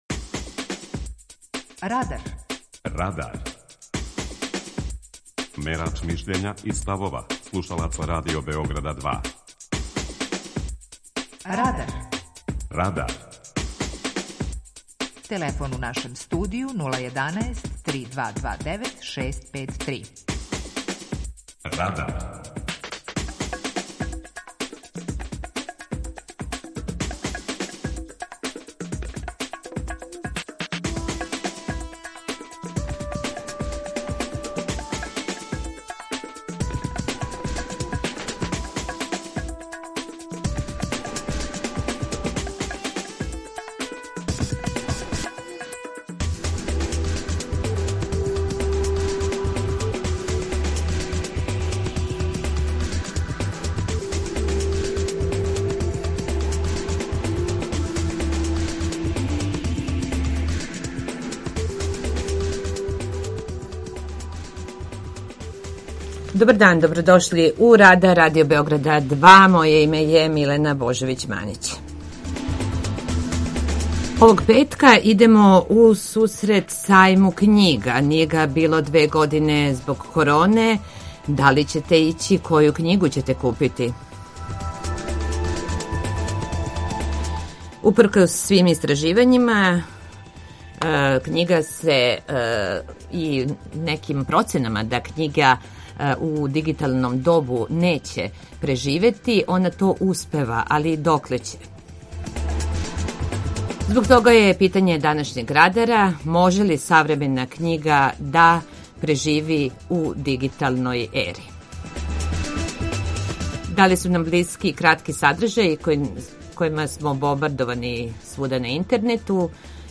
У сусрет Међународном сајму књига у Београду, Радар пита: Може ли штампана књига преживети у дигиталном добу? преузми : 18.59 MB Радар Autor: Група аутора У емисији „Радар", гости и слушаоци разговарају о актуелним темама из друштвеног и културног живота.